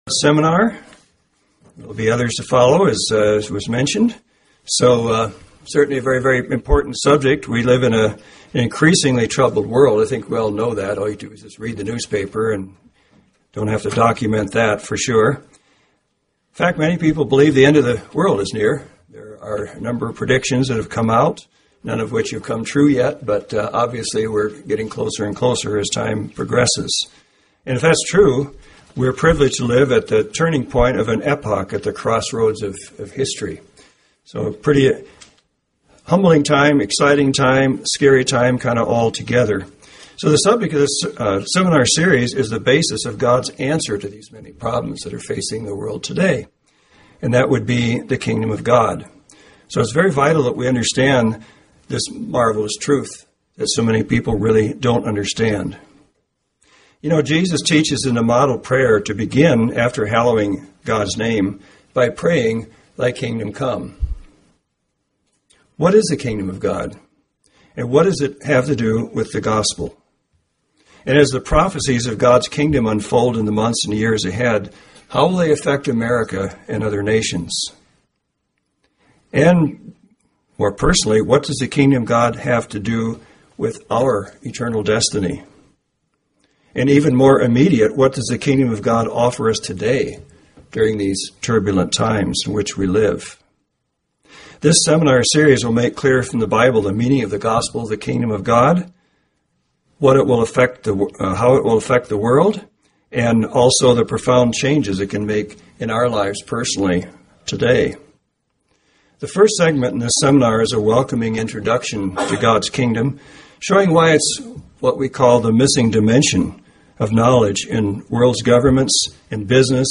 WELCOME TO THE KINGDOM OF GOD! (KOG Seminar #1, Segment I)